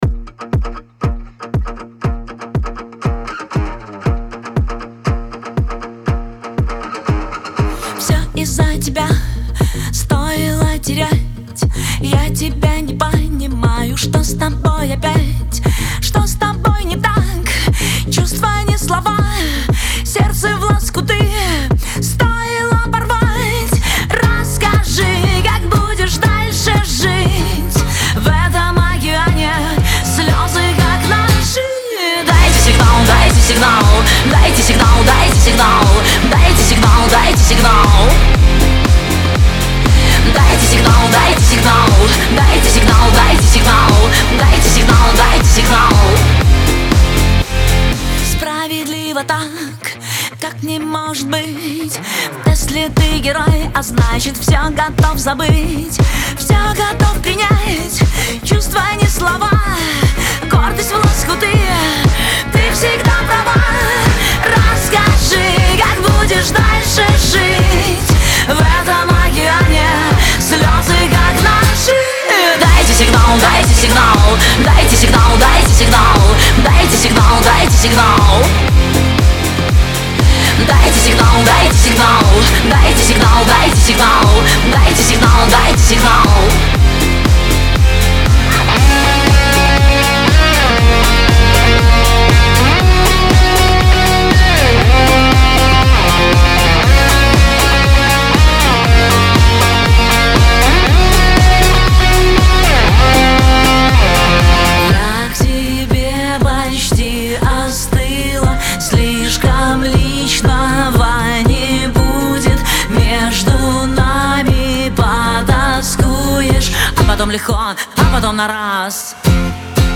pop , диско
эстрада